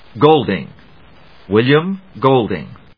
音節Gol・ding 発音記号・読み方/góʊldɪŋgˈəʊl‐/発音を聞く